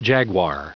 Prononciation du mot jaguar en anglais (fichier audio)
Prononciation du mot : jaguar